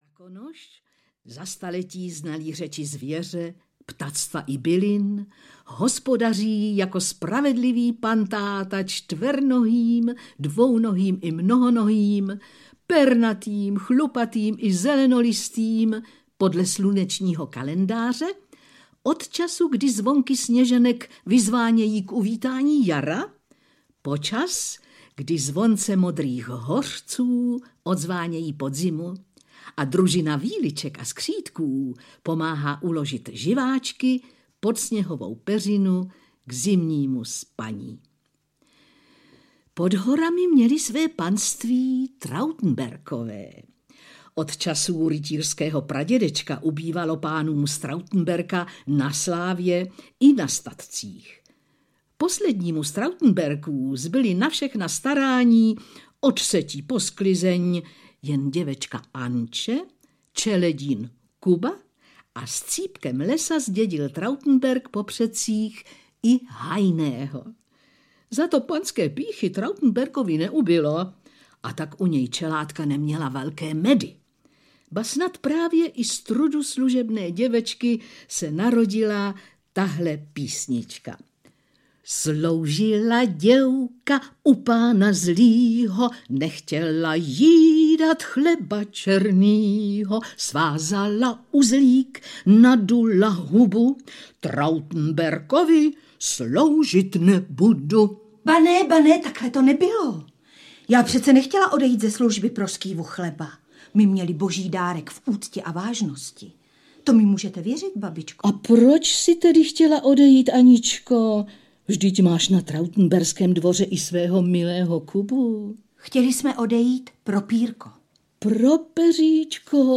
Jak Krakonoš vytrestal Trautenberka audiokniha
Ukázka z knihy
jak-krakonos-vytrestal-trautenberka-audiokniha